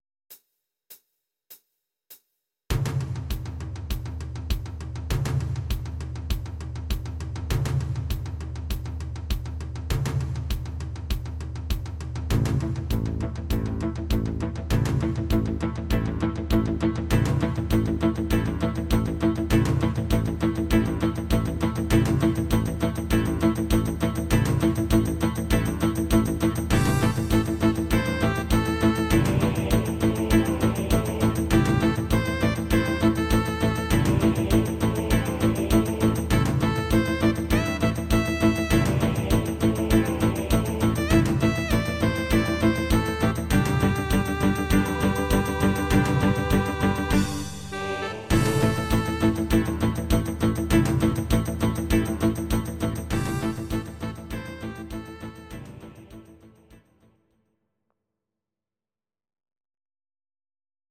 Audio Recordings based on Midi-files
Pop, 1970s